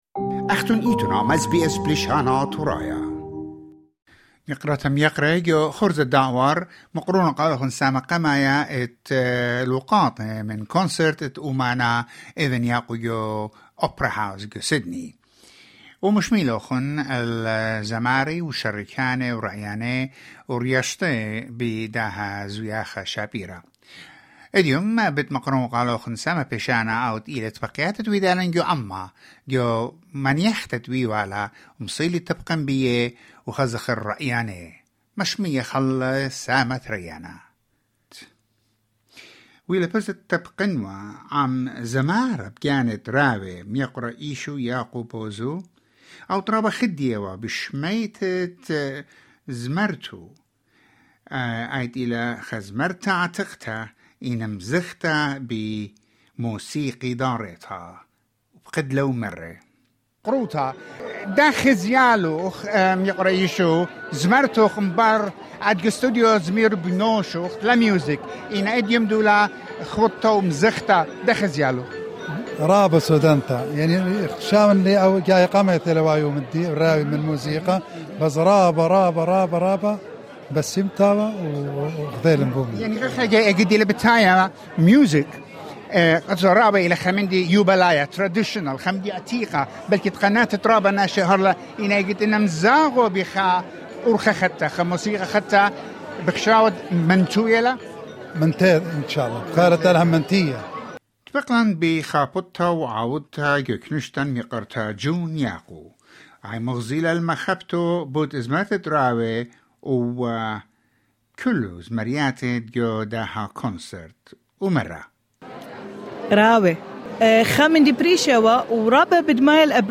Spectators left deeply satisfied with the artistic and creative performance at Australia’s iconic venue. SBS Assyrian captured highlights of these unforgettable moments, showcasing the audience’s appreciation and encouragement for an exceptional evening of music and artistry.